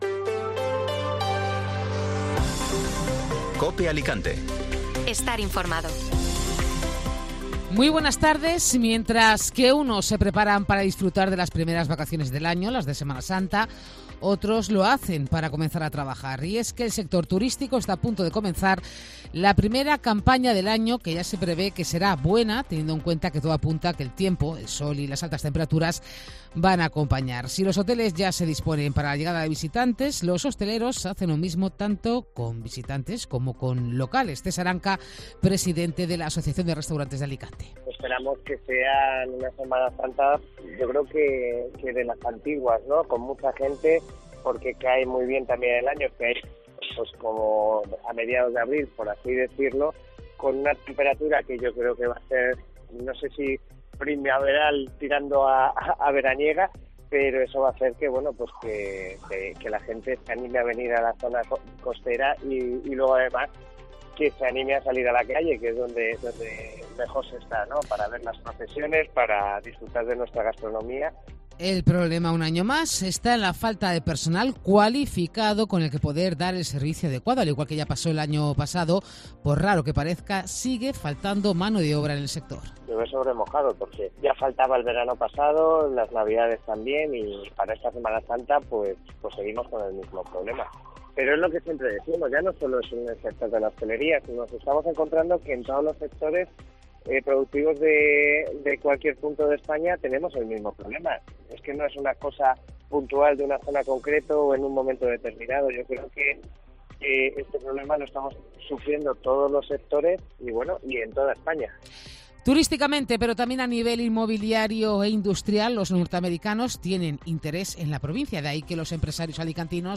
Informativo Mediodía COPE (Lunes 27 de marzo)